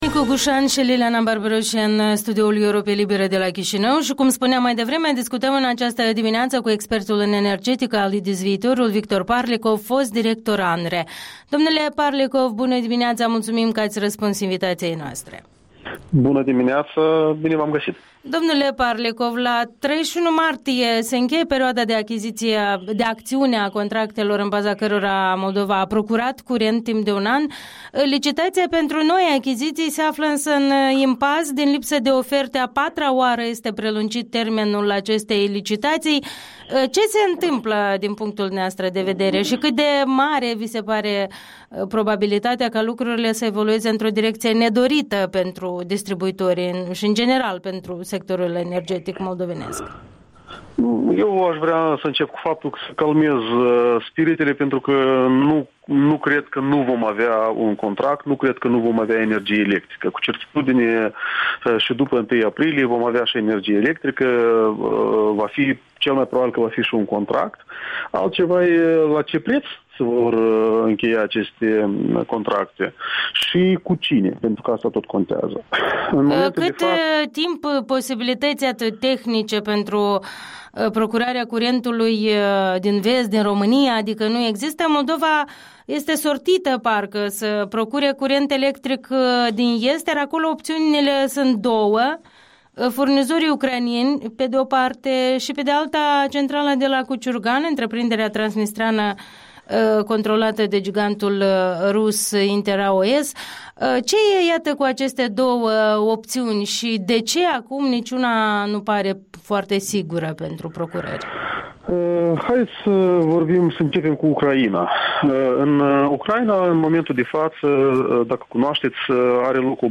Interviul dimineții cu expertul în probleme energetice.